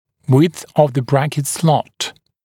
[wɪdθ əv ðə ‘brækɪt slɔt][уидс ов зэ ‘брэкит слот]ширина паза брекета